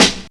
Boom-Bap Snare 76.wav